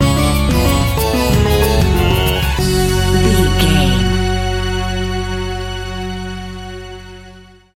Aeolian/Minor
F#
World Music
percussion